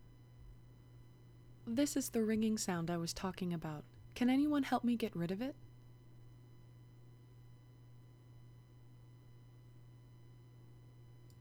Ringing in the Background of my Recordings
I recently purchased a Blue Yeti USB mic, and have had an issue with this high pitched ringing in the background of all my recordings.
As my bad luck would have it, the sound is softer today, but definitely still there. There is some background noise as well, but that’s not what my issue.
It’s interference : the 1000Hz + harmonics from the computer’s power supply , and in your case an additional +/- 120Hz component from the second harmonic of your mains electricity ( 60Hz) …